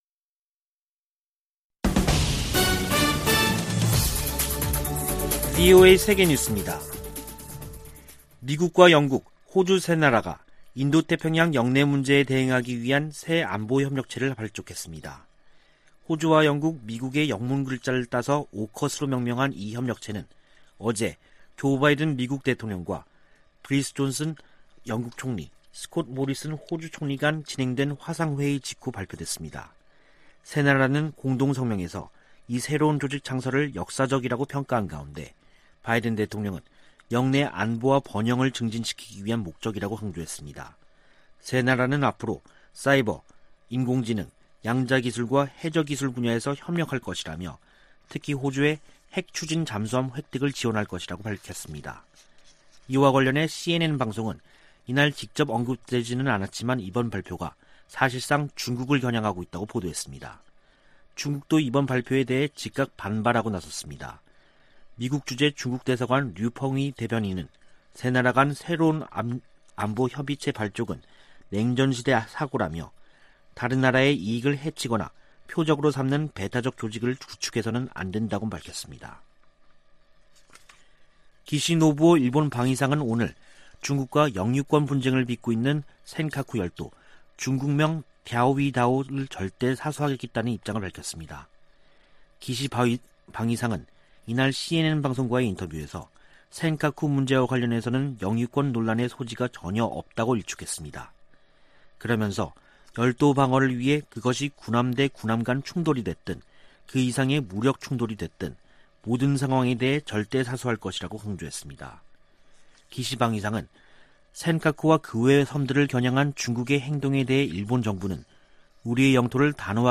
VOA 한국어 간판 뉴스 프로그램 '뉴스 투데이', 2021년 9월 16일 2부 방송입니다. 미국 정부가 북한의 탄도미사일 발사를 강하게 규탄했습니다. 유엔 안보리 긴급 회의에서 북한의 최근 단거리 탄도미사일 발사에 대해 논의했습니다. 미국 국무부 국제안보ㆍ비확산 담당 차관보 지명자가 인준받아 임명되면 북한 문제를 최우선 과제로 삼을 것이라고 밝혔습니다.